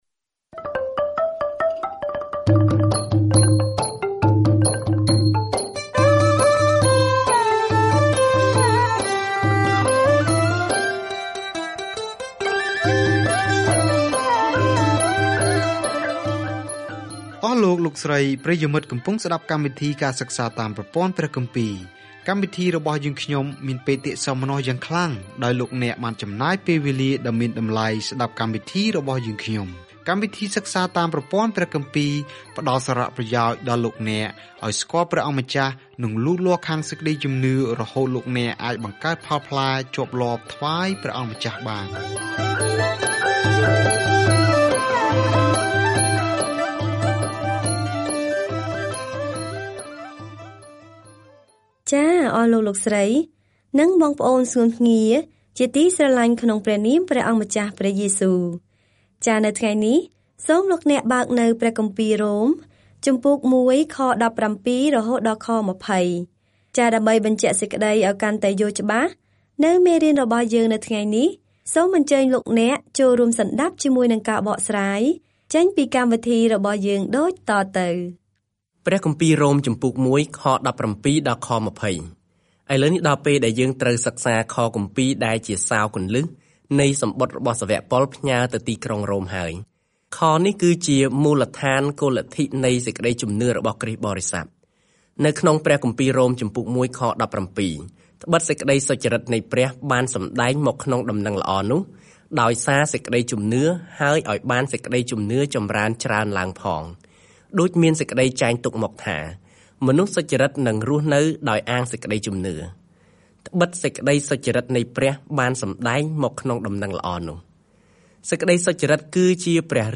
ការធ្វើដំណើរជារៀងរាល់ថ្ងៃតាមរយៈជនជាតិរ៉ូម ពេលអ្នកស្តាប់ការសិក្សាជាសំឡេង ហើយអានខគម្ពីរដែលជ្រើសរើសពីព្រះបន្ទូលរបស់ព្រះ។